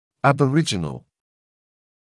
[ˌæbə’rɪʤənl][ˌэбэ’риджэнл]абориген, коренной житель; аборигенский, исконный